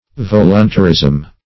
Voluntarism \Vol"un*ta*rism\, n.